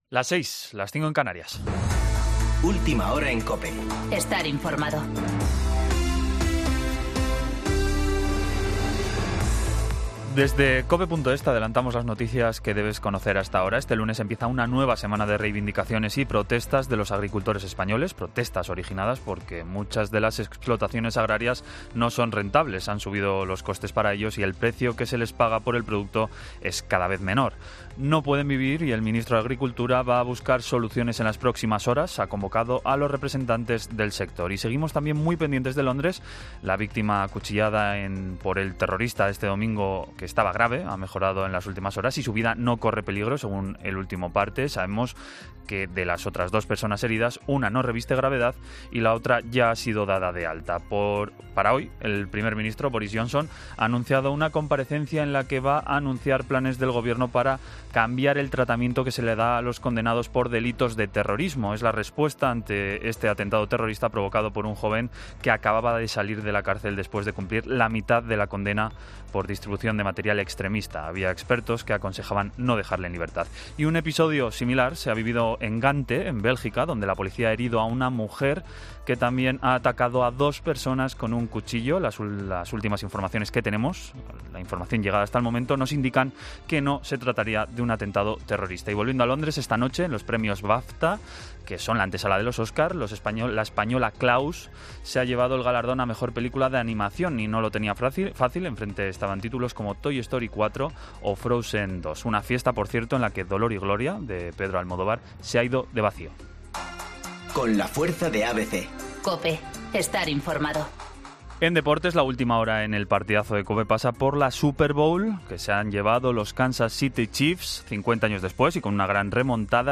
Boletín de noticias COPE del 3 de febrero de 2020 a las 06.00 horas